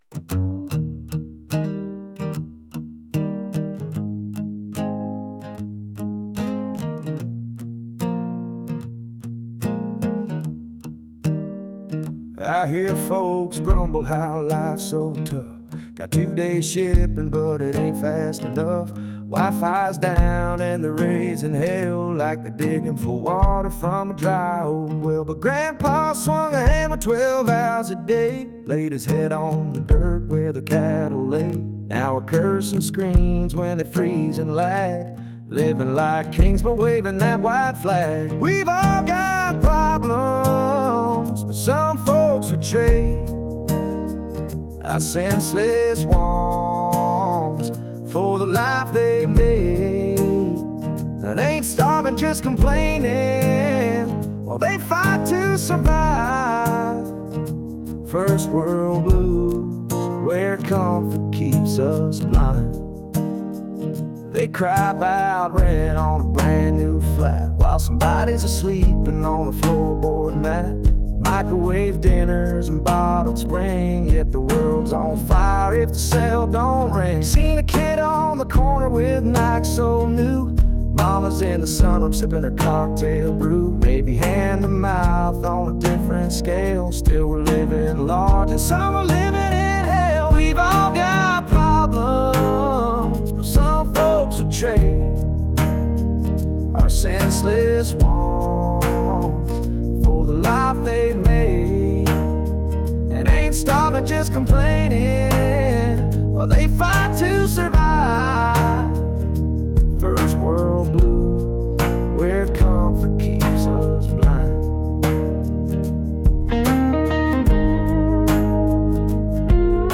Blues, Country